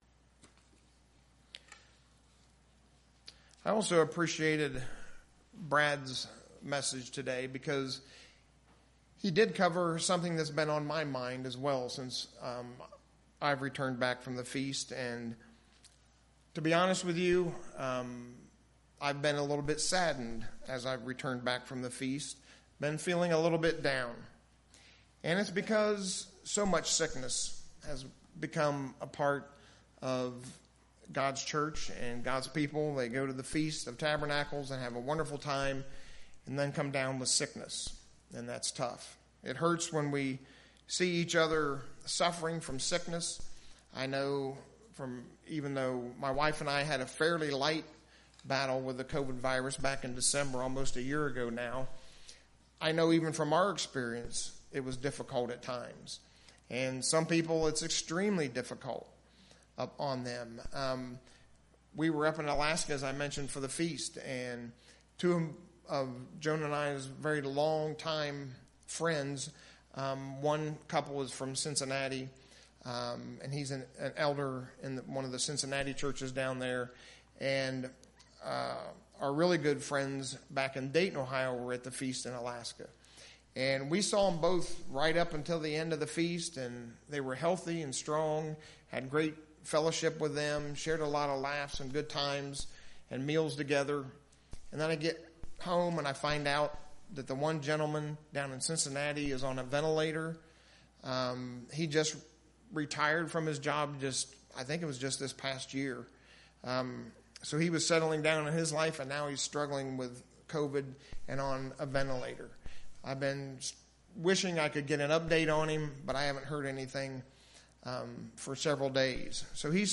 In the sermon today, we'll see just how much God cares for you.